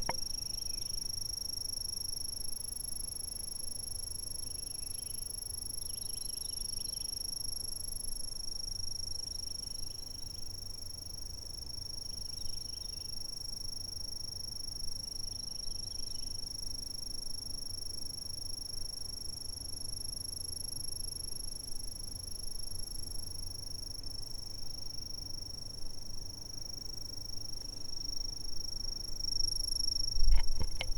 insects